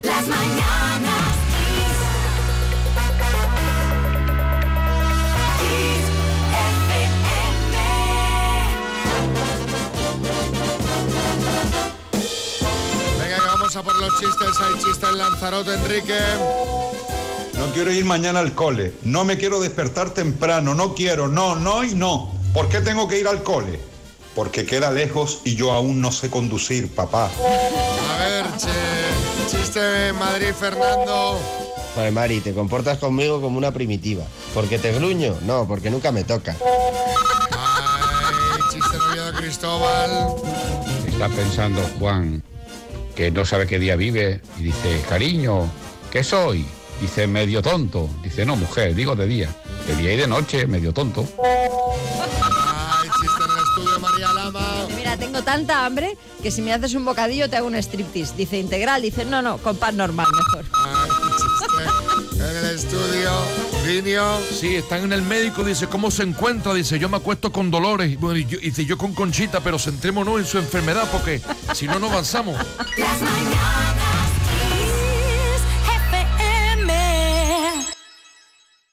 Los oyentes hacen sus mejores aportaciones.